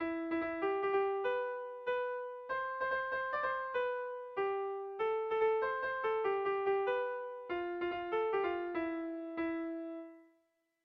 Kopla handia
ABD